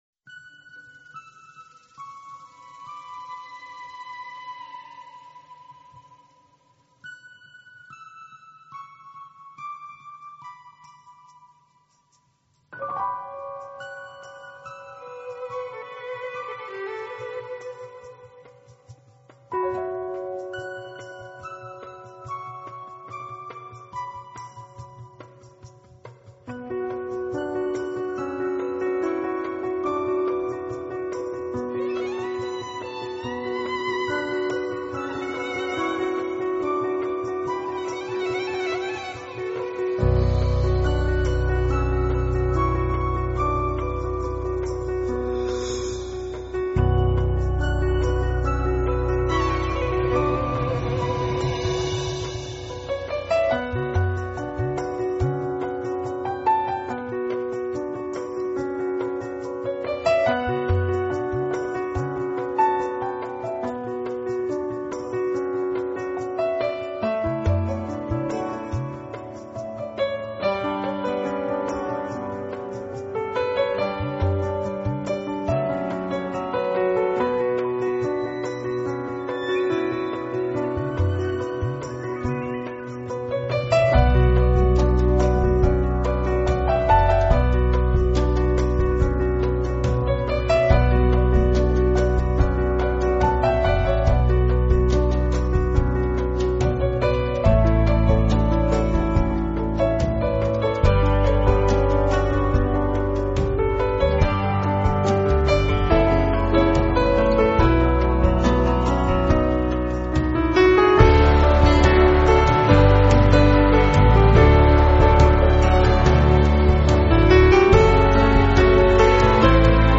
类型：New Age
风格：Contemporary Instrumental